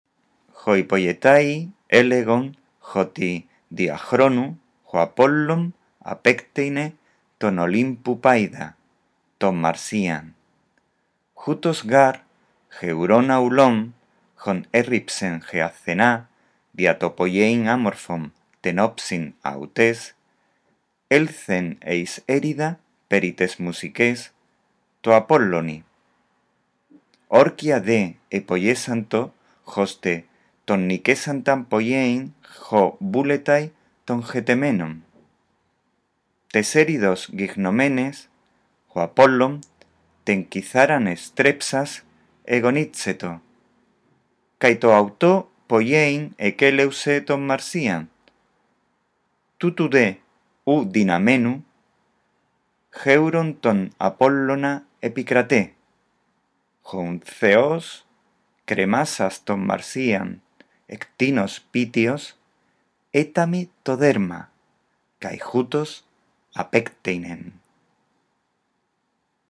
Escucha atentamente este archivo de audio y repite la lectura del texto que describe la disputa entre Apolo y Marsias.